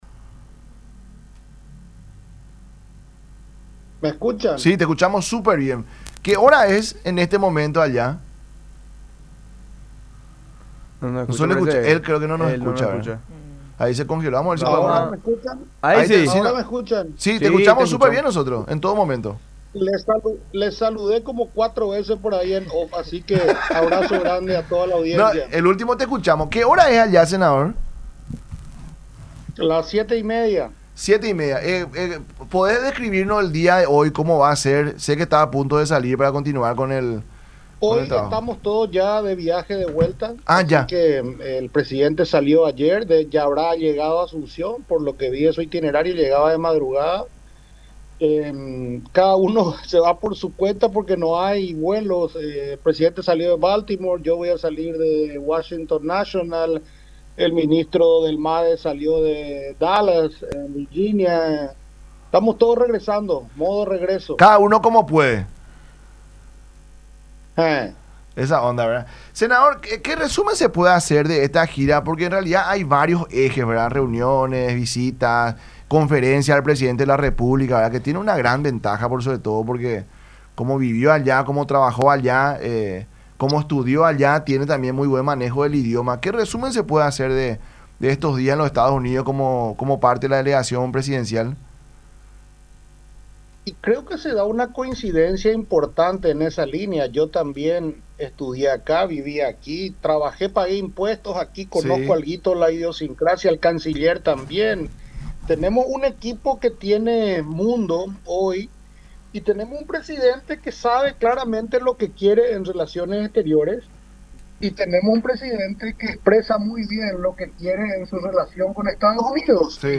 “Es la primera vez que hacemos una incursión como esta. Hablamos con ocho senadores”, dijo en el programa “La Mañana De Unión” por radio La Unión y Unión Tv.